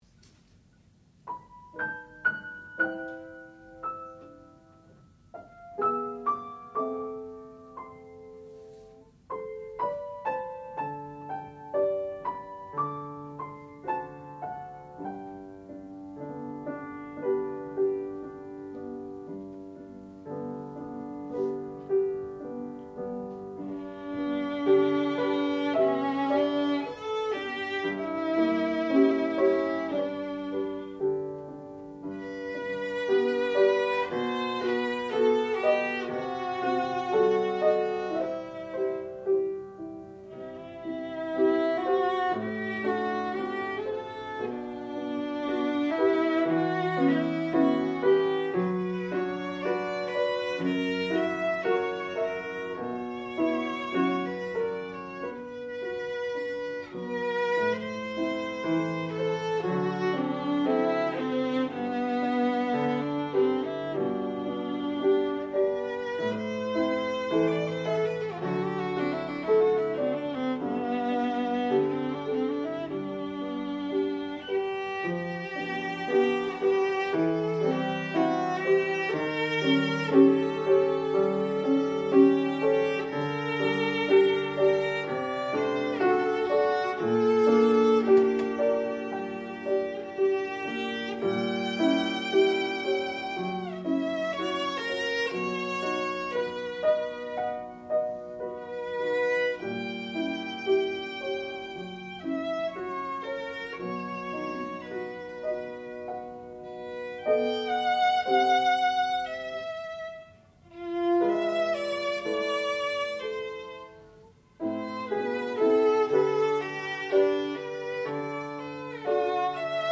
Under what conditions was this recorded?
Advanced Recital